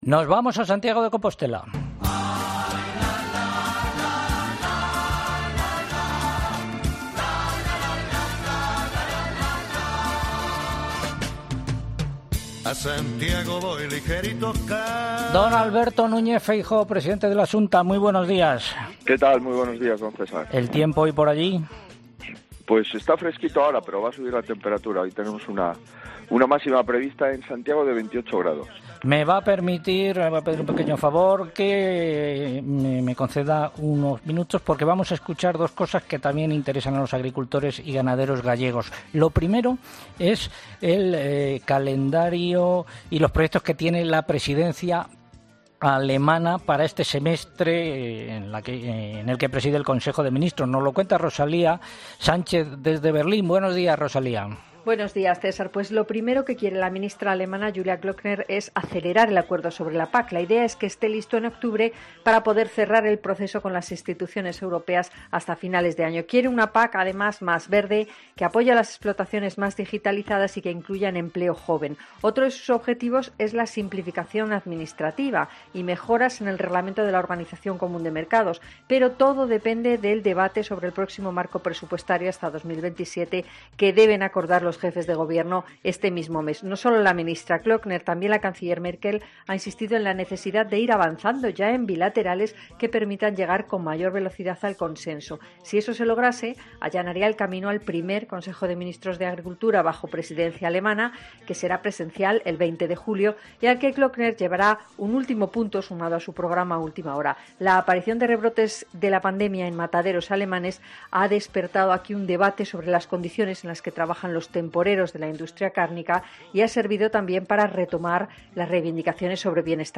Feijóo, en COPE: “Nos amenazan con una subida de impuestos y vamos por el camino contrario”